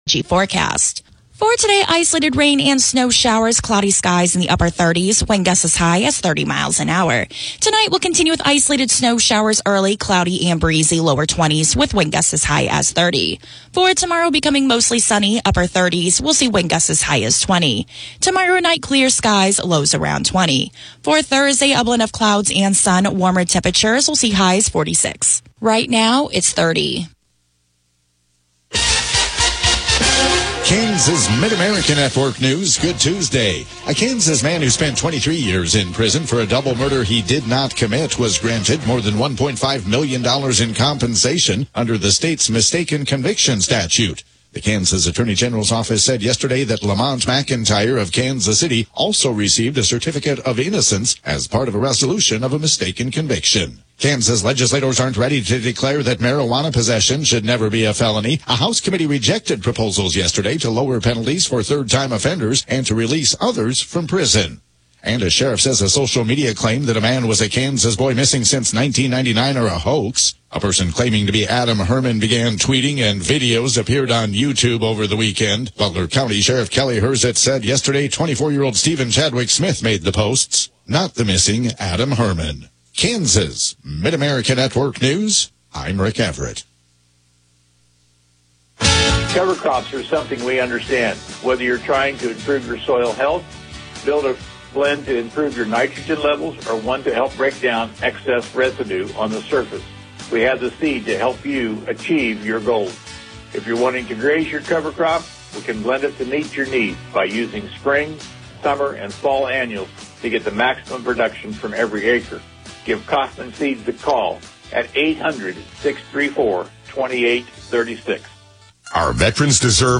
The KNDY News: Morning Edition podcast gives you local, regional and state news as well as relevant information for your farm or home as well. Broadcasts are archived daily as originally broadcast on Classic Country FM 94.1 KNDY.